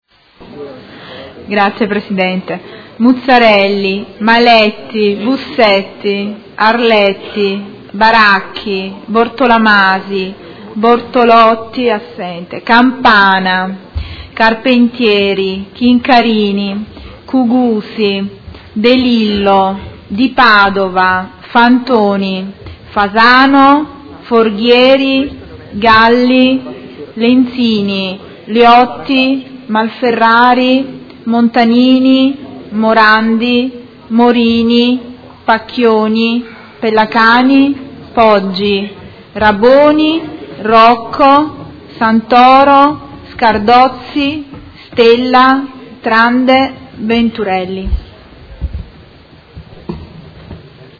Segretario Generale — Sito Audio Consiglio Comunale
Appello